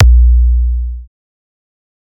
Puja Special All Type Dhak Dj Remix Collection